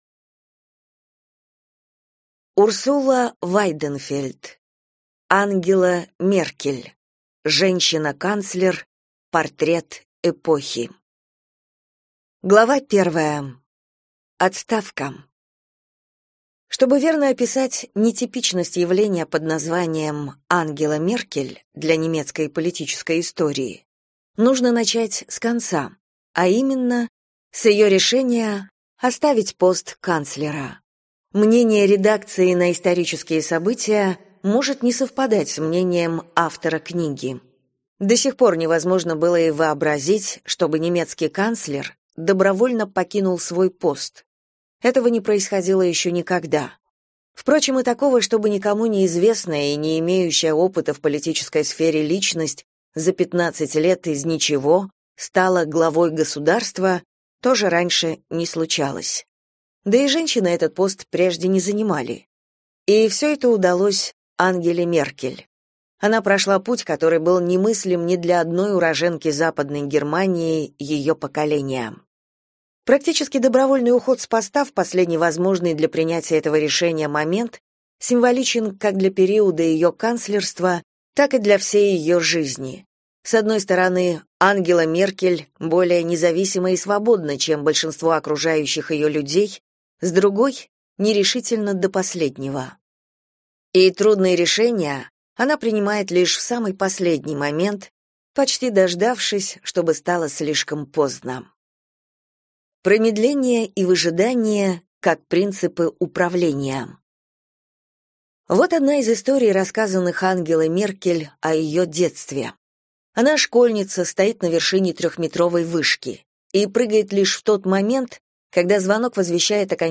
Аудиокнига Ангела Меркель. Женщина – канцлер. Портрет эпохи | Библиотека аудиокниг